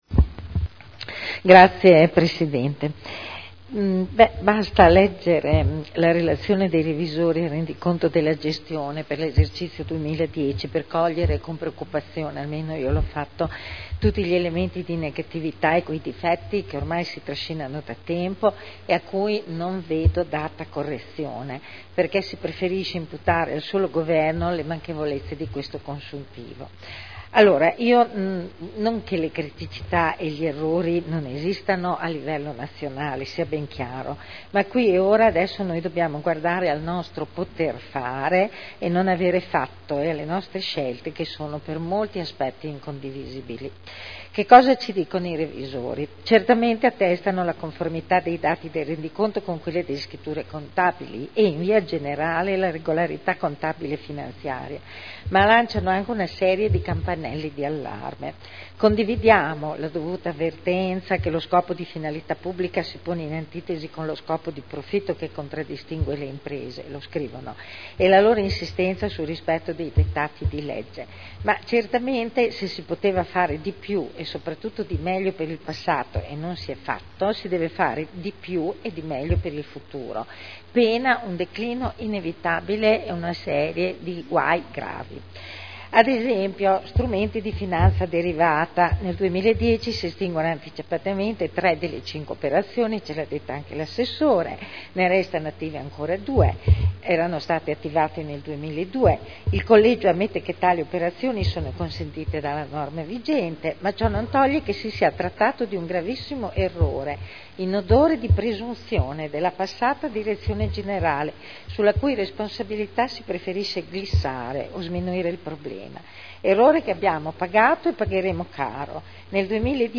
Seduta del 28/04/2011. Dibattito su Delibera: Rendiconto della gestione del Comune di Modena per l’esercizio 2010 – Approvazione (Commissione consiliare del 21 aprile 2011)